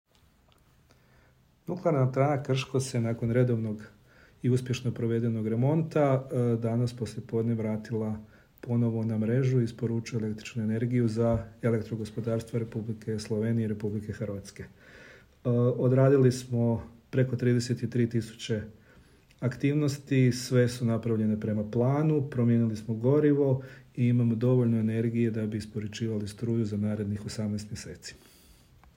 Tonska izjava